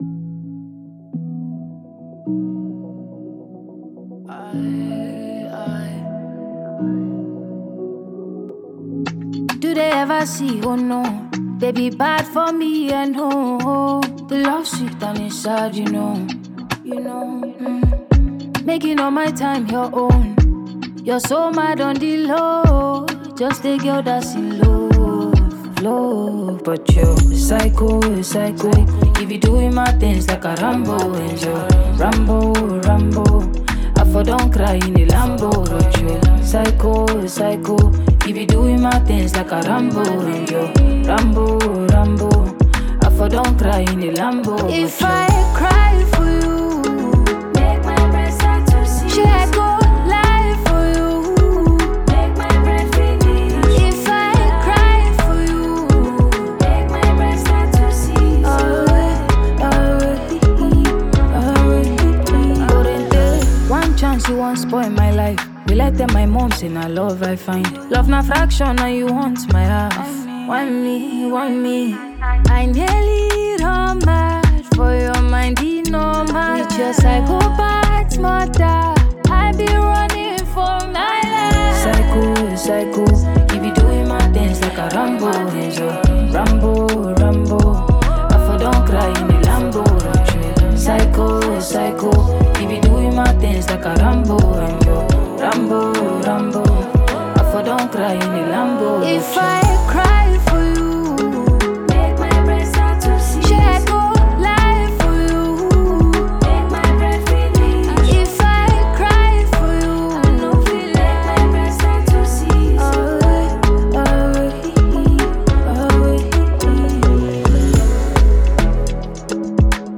a Nigerian female singer, songwriter, and vocalist
seductive